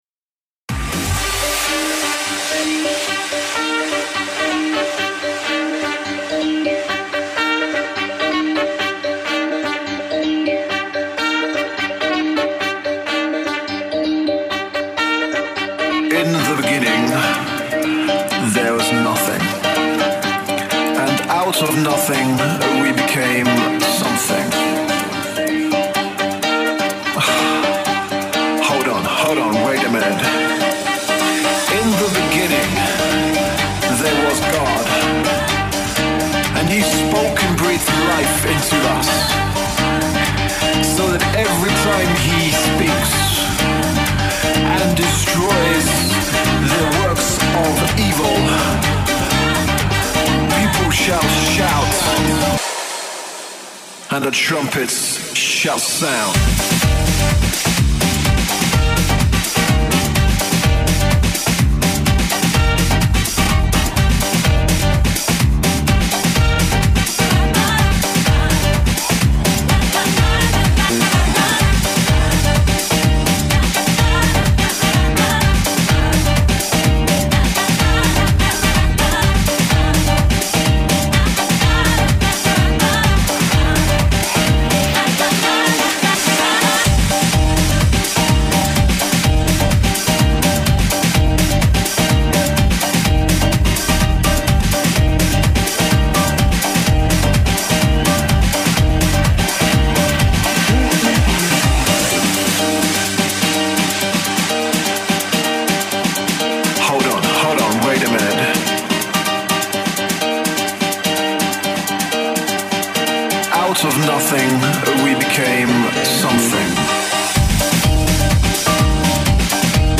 A selection of jazzy funk – Lots of horns and good rhythms